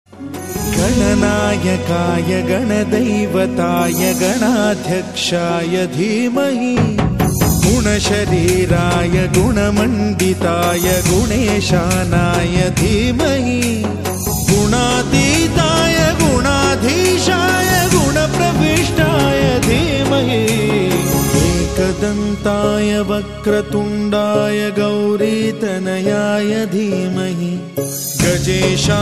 File Type : Bhajan mp3 ringtones